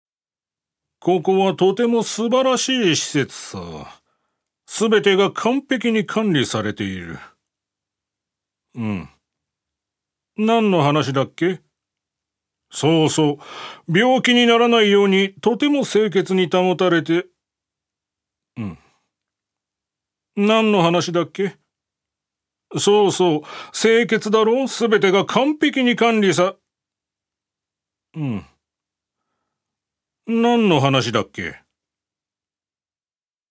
所属：男性タレント
音声サンプル
セリフ３